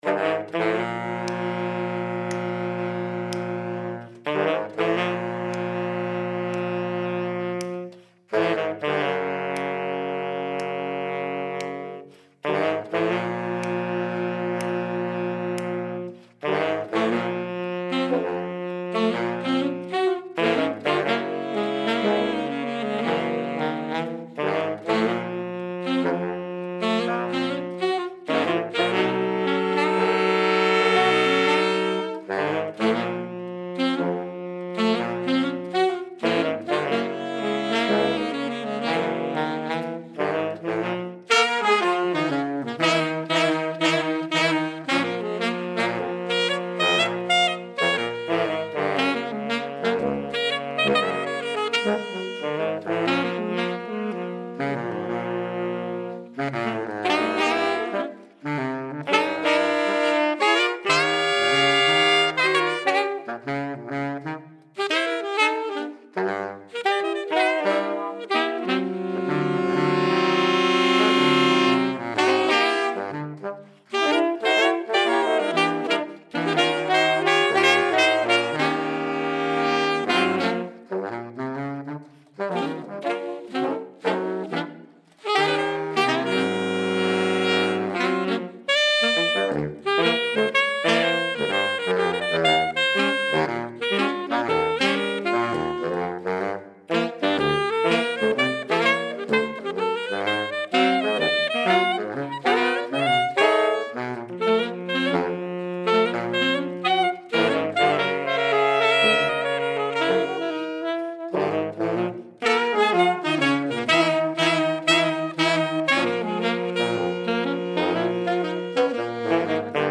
Séance d'enregistrement de l'ensemble de Saxophones
sax
le Baryton, le Ténor, l'Alto et le Soprano (dans l'ordre, de la tessiture la plus grave à la plus aigüe).
Chaque instrument a été enregistré avec un micro séparé.